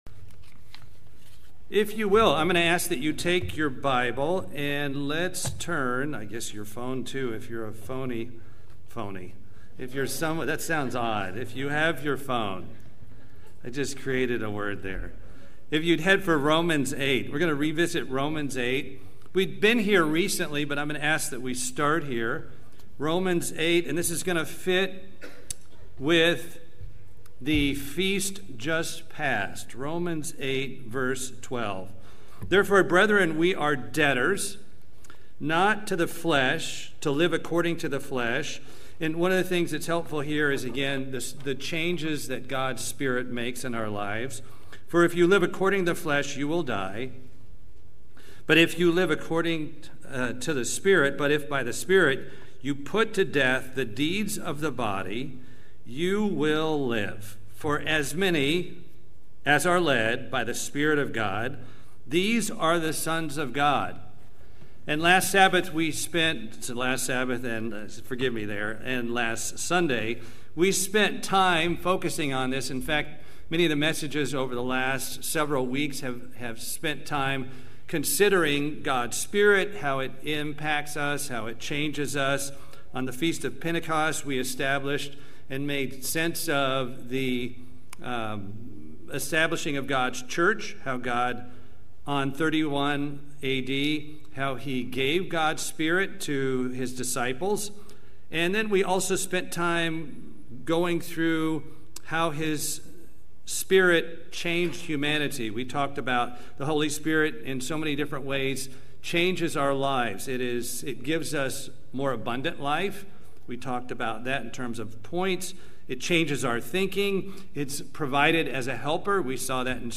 This sermon gives the background and spiritual significance of the Biblical Wave Sheaf Offering and how Christ fits in!